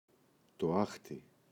άχτι, το [‘axti] – ΔΠΗ